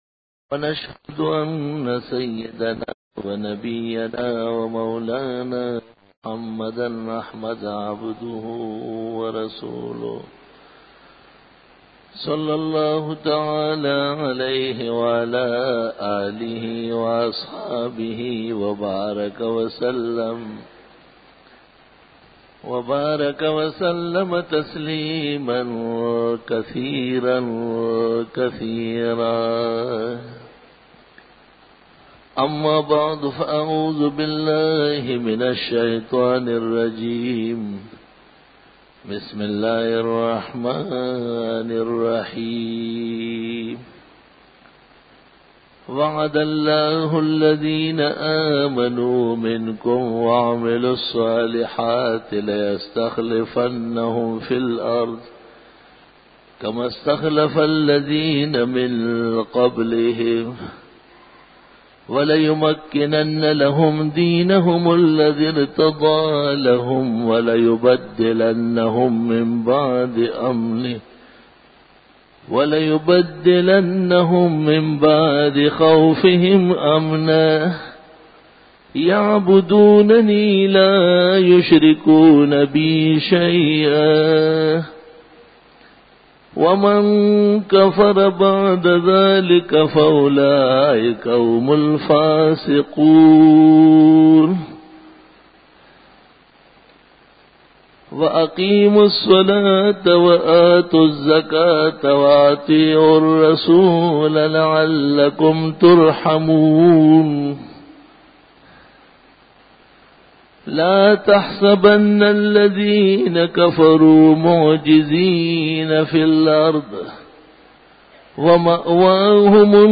015_Jummah_Bayan_19_Apr_2002
بیان جمعۃ المبارک